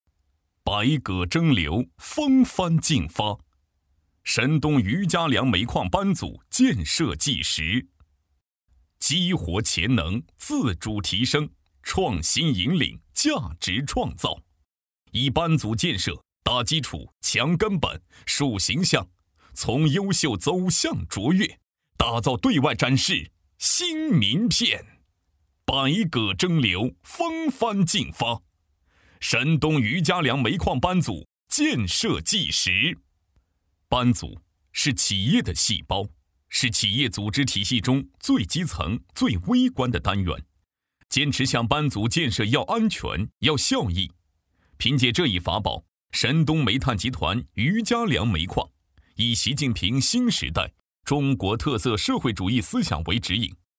大气浑厚 企业专题,人物专题,医疗专题,学校专题,产品解说,警示教育,规划总结配音